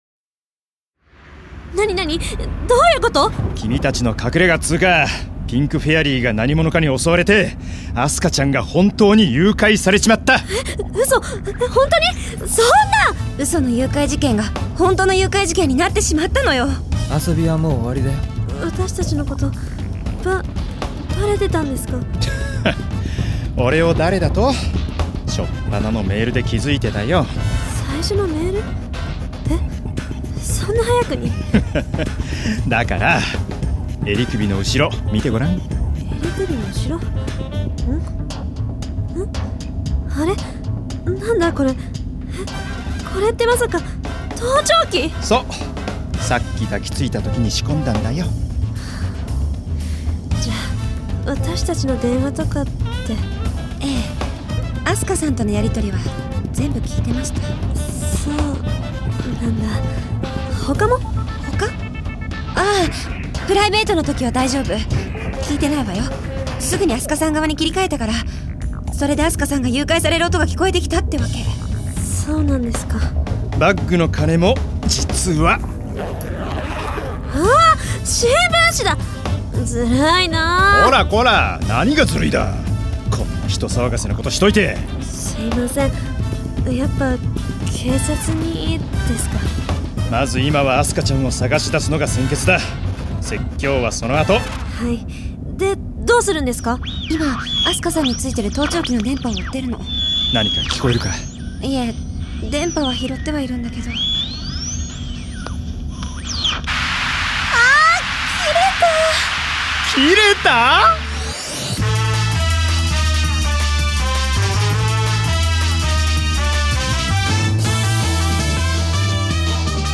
出演：小山力也 秋山莉奈 田中敦子 他
メイドカフェ・メイリッシュ協力のもと、かつてない臨場感で迫ります。